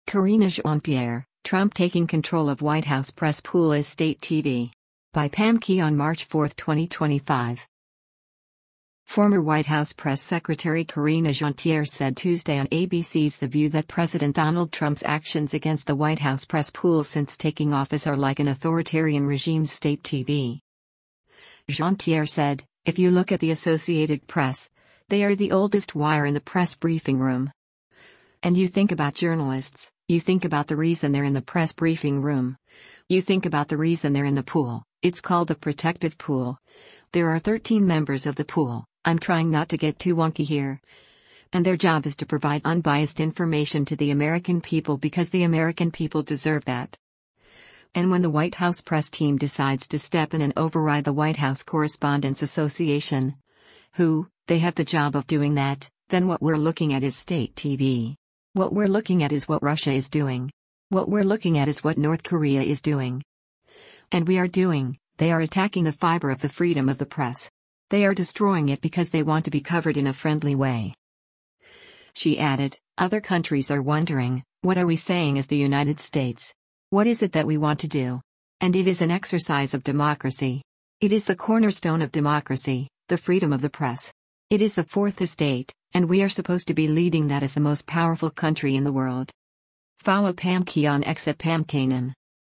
Former White House press secretary Karine Jean-Pierre said Tuesday on ABC’s “The View” that President Donald Trump’s actions against the White House press pool since taking office are like an authoritarian regime’s state TV.